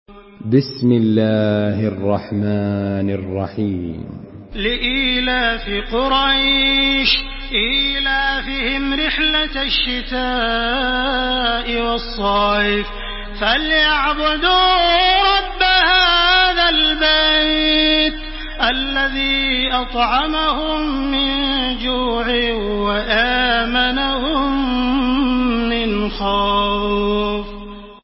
سورة قريش MP3 بصوت تراويح الحرم المكي 1429 برواية حفص
مرتل